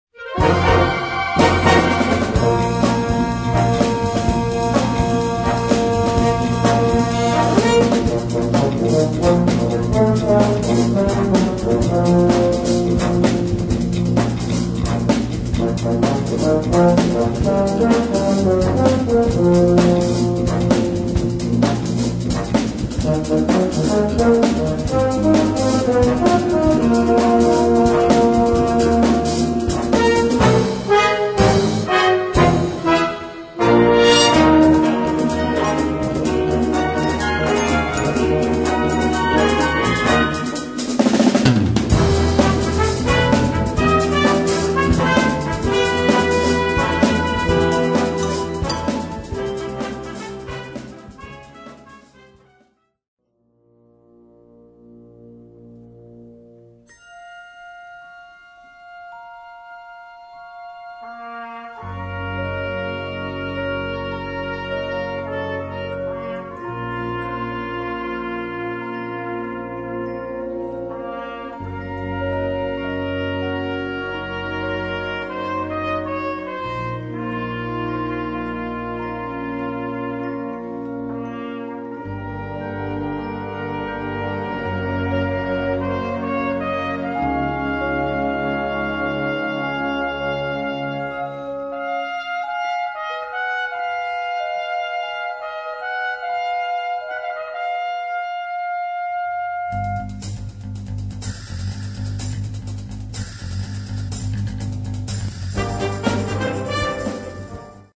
Soundtrack
Besetzung: Blasorchester